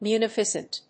発音記号
• / mjuːnífəsnt(米国英語)